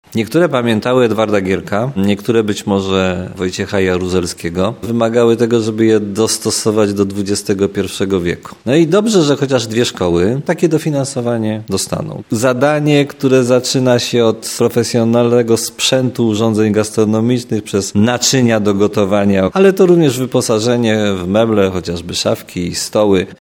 Standard stołówek zostanie podniesiony w Szkole Podstawowej numer 10 i w Zespole Szkół Specjalnych. Mówi prezydent Tarnobrzega, Dariusz Bożek.